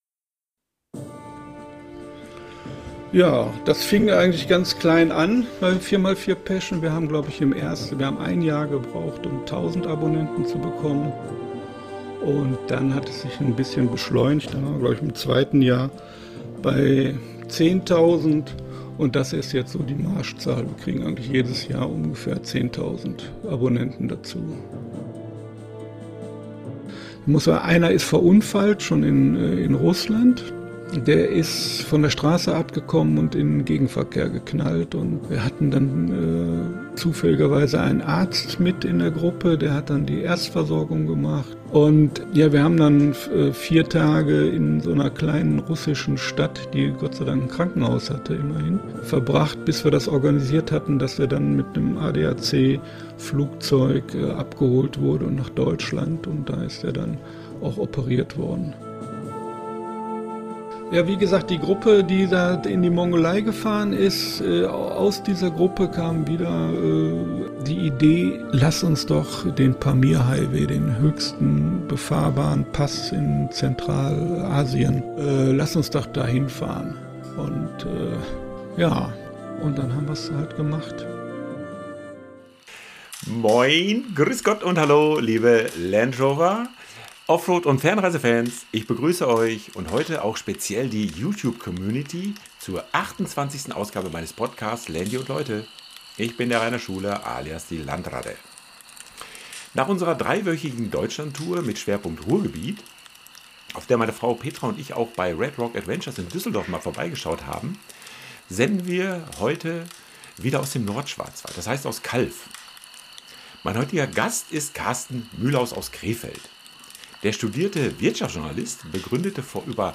Also macht es euch jetzt bitte am Laberfeuer bequem und genießt den Campfire Talk to go.